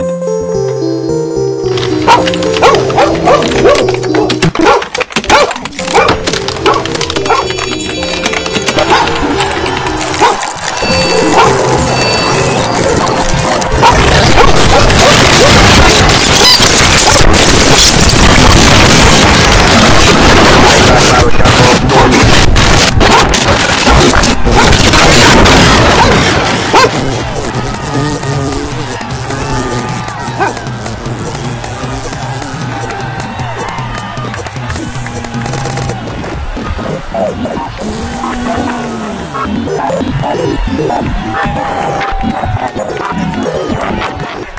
描述：Drumloopan average 100.012bpm (Commodore C64'ish to avoid legal problems... gosh...我的意思是，这不是真实的东西，人们可以看出来！！）。
标签： 压碎 毛刺 循环 错位 噪声 噪声 配音 愤怒 无用
声道立体声